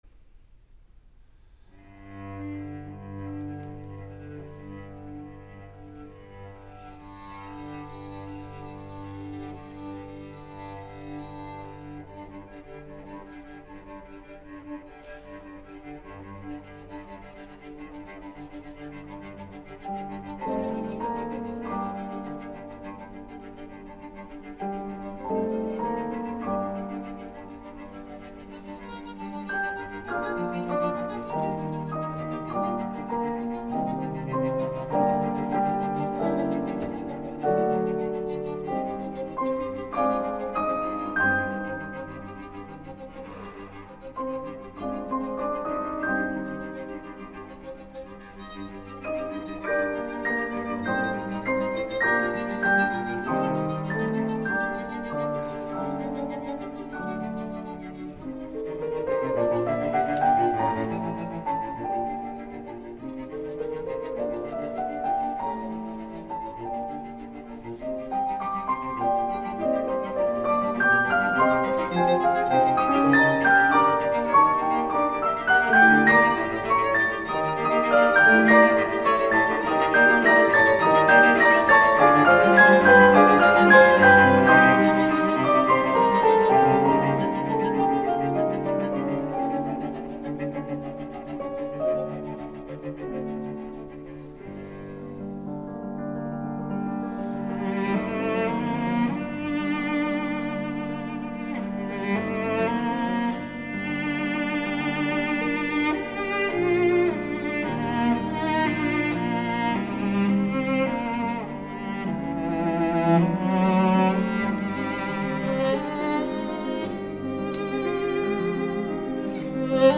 Рахманинов_Элегическое трио № 1 соль минор